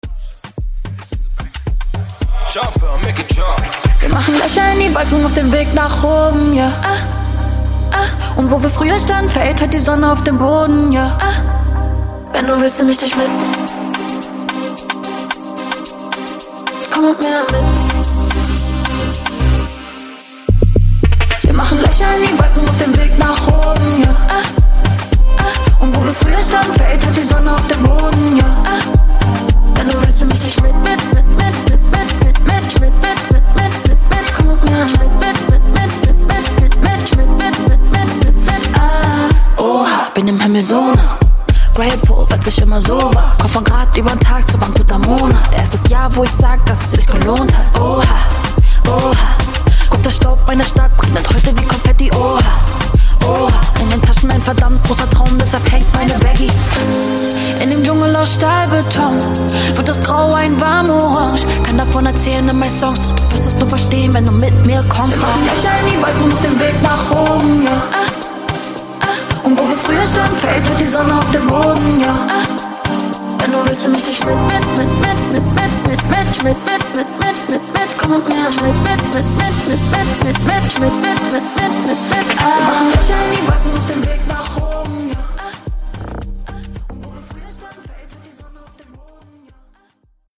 Genres: DANCE , RE-DRUM , TOP40 Version: Clean BPM: 92 Time